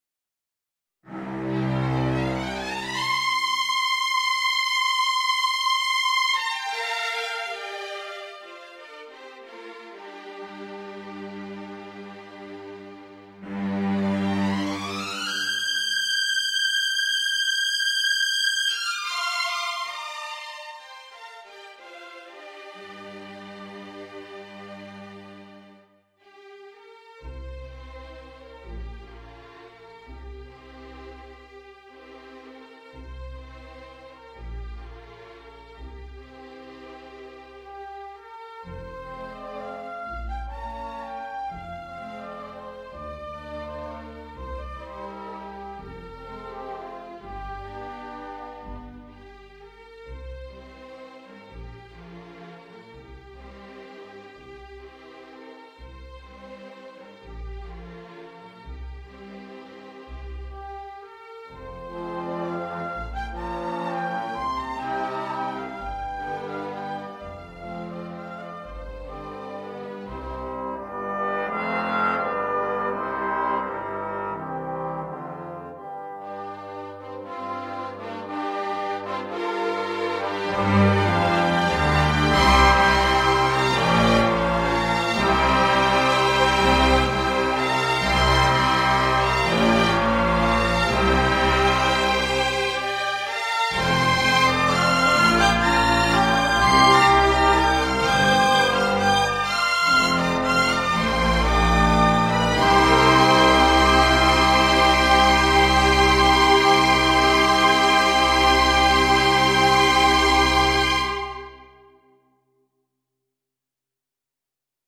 This uses neutral triads below a mostly-5edo melody.
in the neopentatonicist style